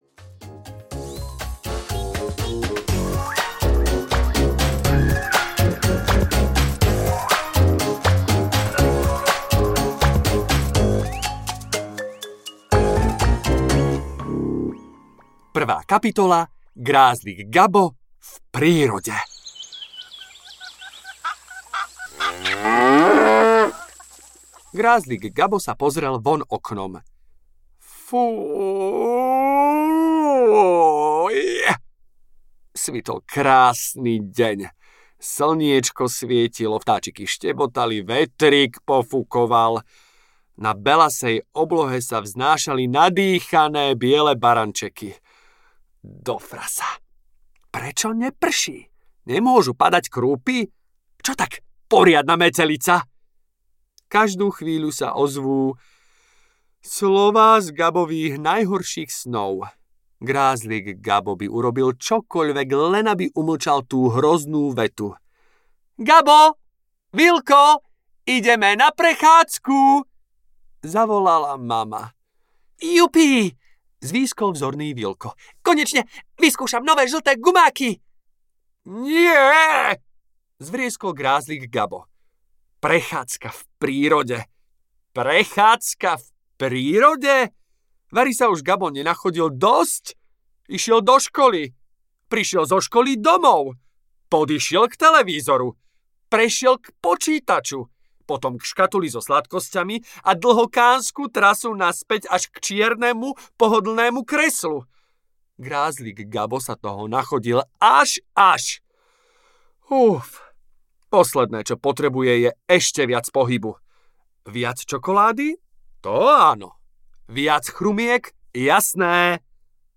Audio knihaGrázlik Gabo a ničivý časostroj
Ukázka z knihy